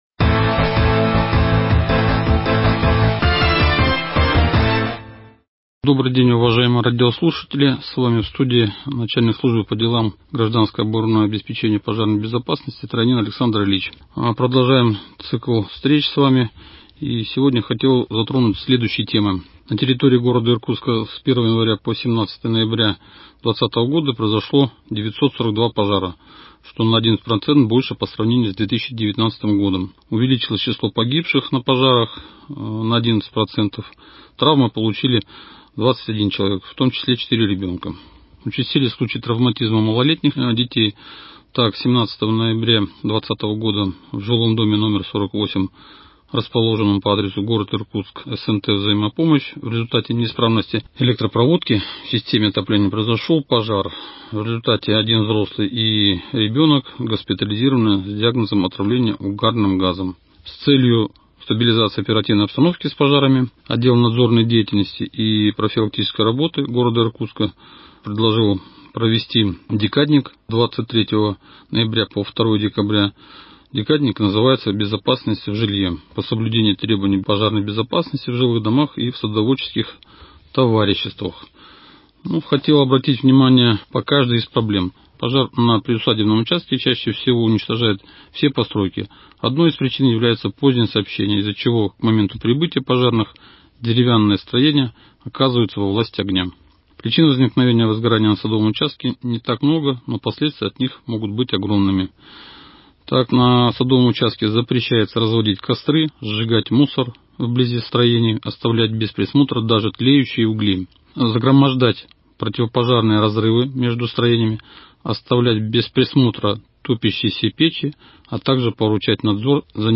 Радиожурнал МКУ «Безопасный город информирует» 25.11.2020